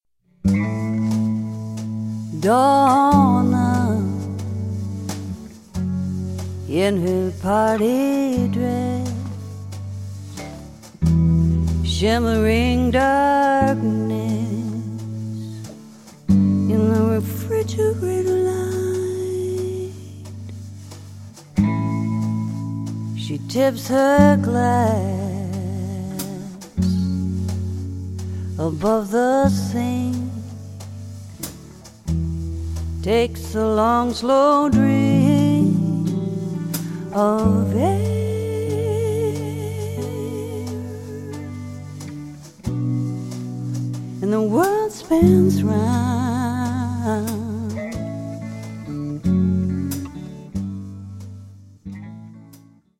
Drums
Vocals, Guitar